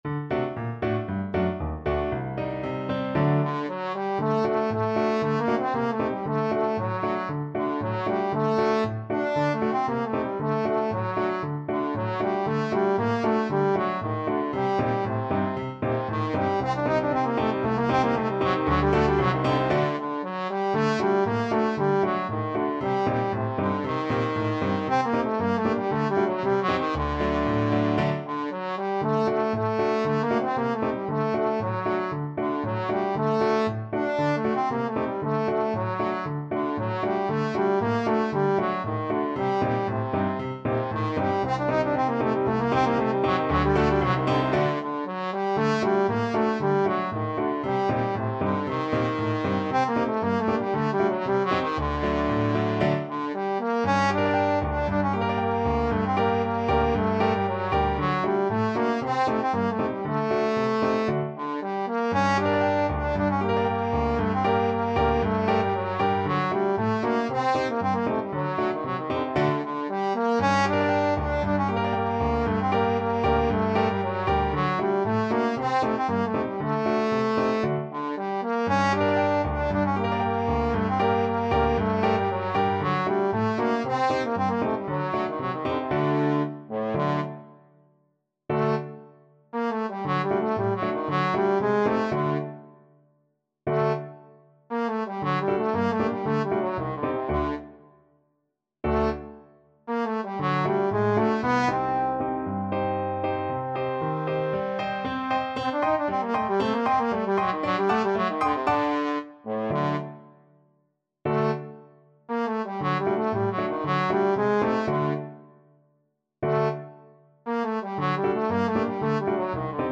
Trombone
2/4 (View more 2/4 Music)
A3-Eb5
G minor (Sounding Pitch) (View more G minor Music for Trombone )
Allegro =c.116 (View more music marked Allegro)
Traditional (View more Traditional Trombone Music)
world (View more world Trombone Music)